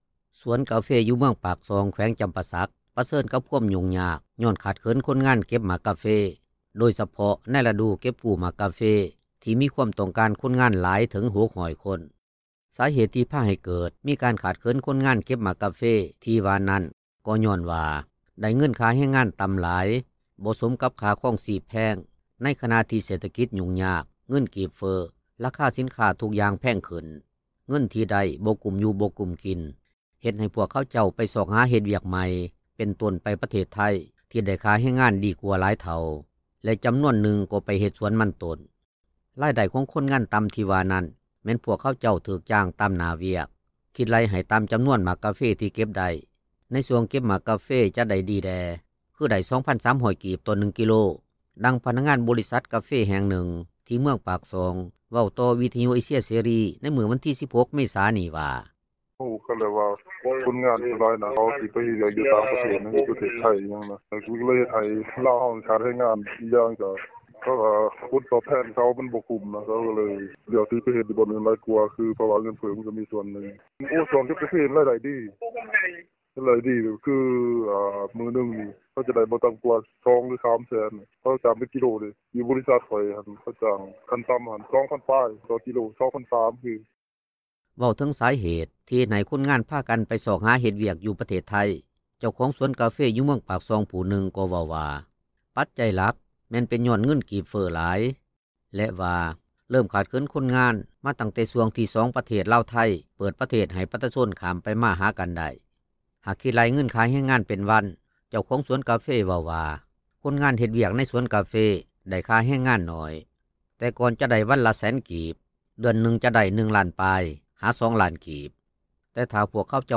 ດັ່ງພະນັກງານບໍລິສັດກາເຟແຫ່ງນຶ່ງ ທີ່ເມືອງປາກ ຊ່ອງ ເວົ້າຕໍ່ວິທຍຸເອເຊັຽເສຣີ ໃນມື້ວັນທີ 16 ເມສານີ້ວ່າ: